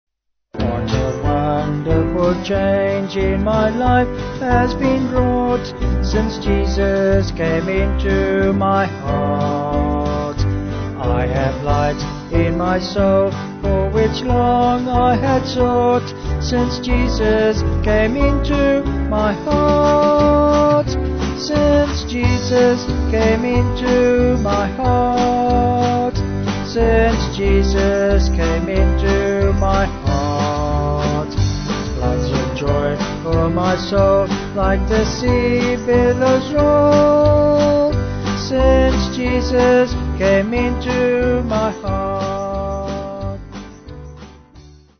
Vocals and Band
263.8kb Sung Lyrics 3.3mb